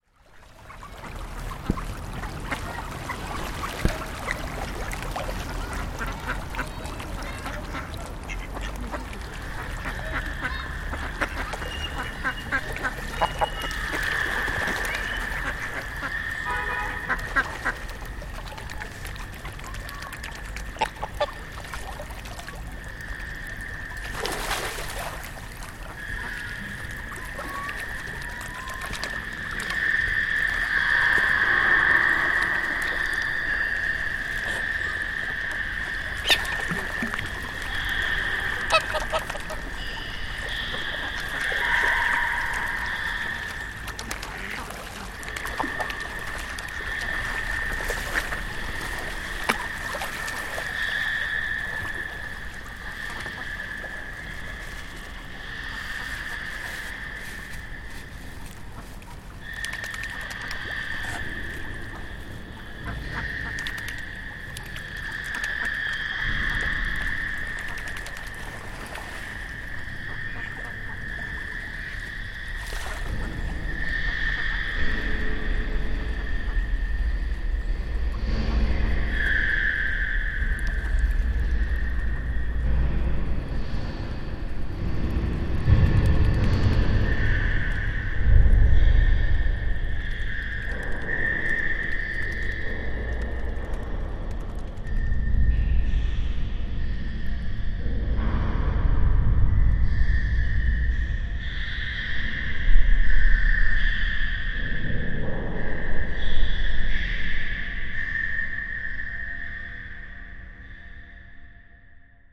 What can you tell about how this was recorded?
Hydrophone recording of feeding the ducks from underwater mixed in with the 'out of the water' recording to create a new soundscape.